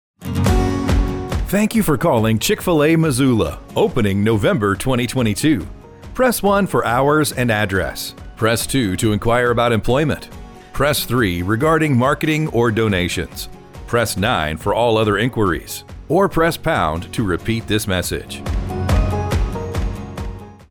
Auto Attendant